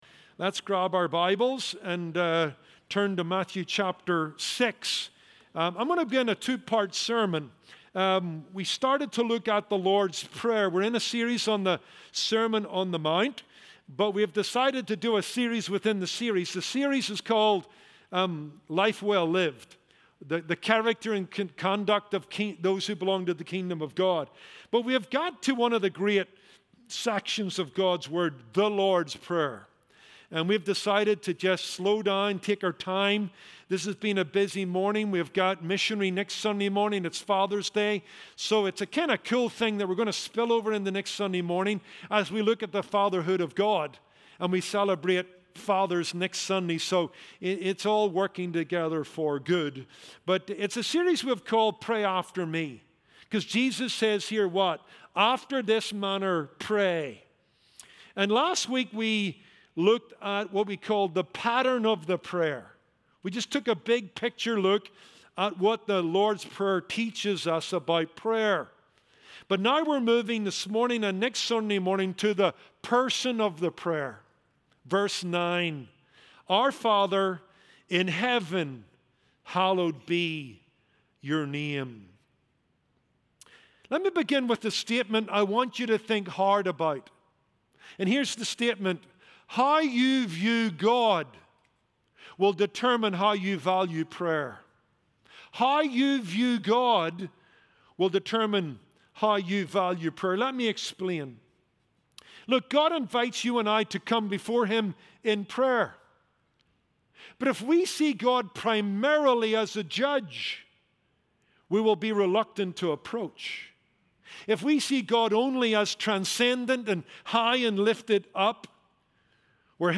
Sermons from the Pulpit of Kindred Community Church